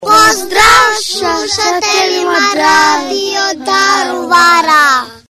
A djeca svi u jedan glas pozdravila su slušatelje Radio Daruvara
Djeca pozdrav
DJECA-POZDRAV.mp3